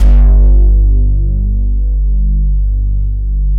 110 BASS  -L.wav